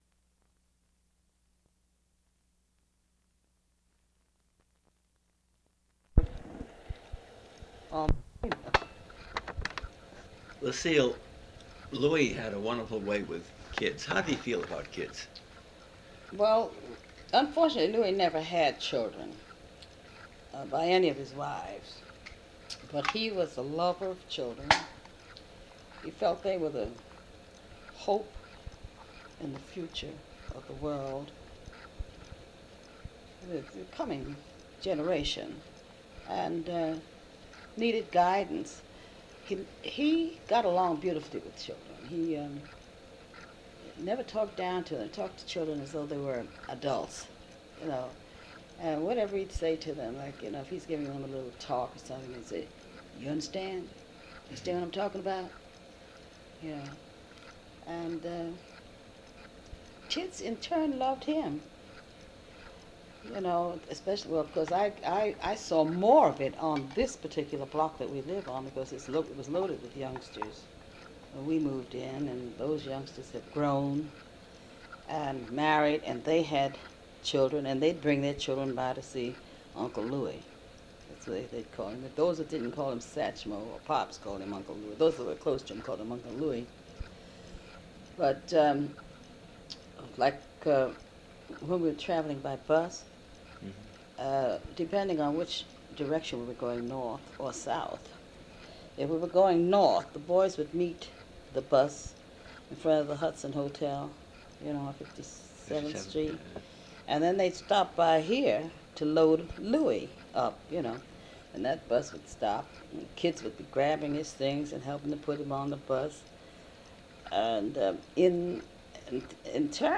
Lucille-Interview-with-George-T.-Simon-Side-1.mp3